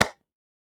High Five A.wav